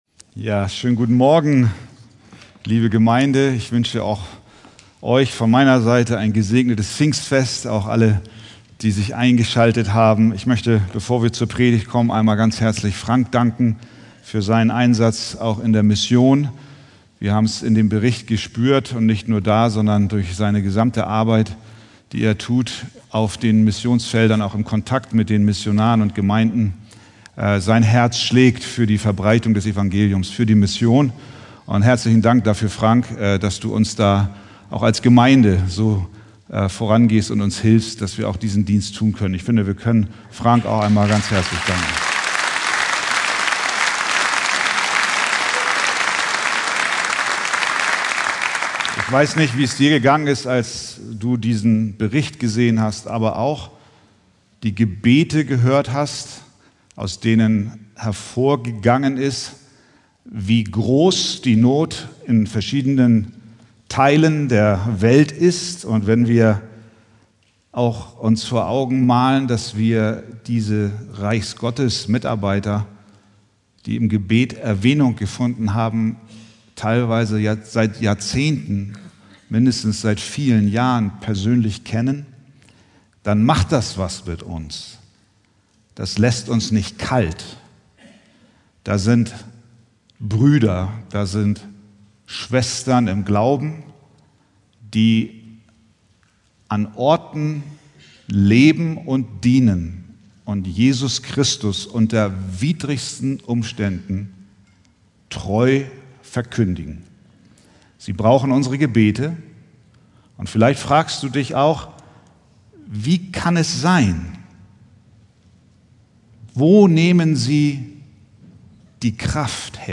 Predigttext: 2. Timotheus 1,7